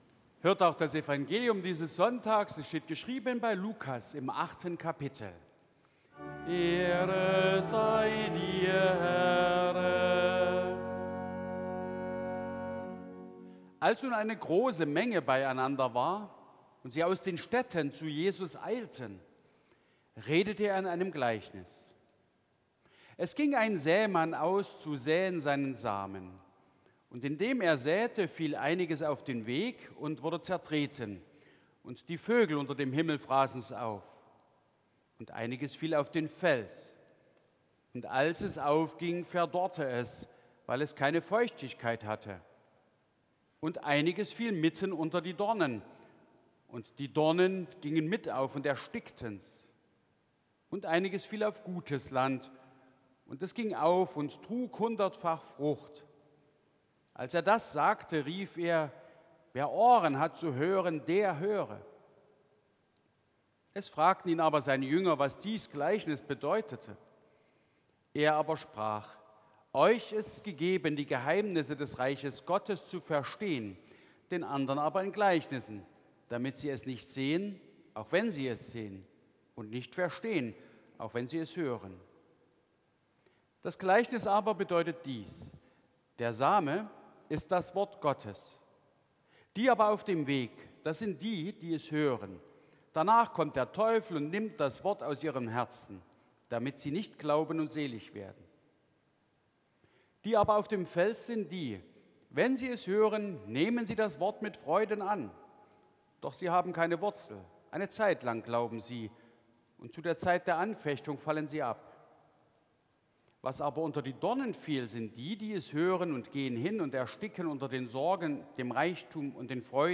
Gottesdienst am 23.02.2025
Lesung aus Lukas 8,4-15 Ev.-Luth.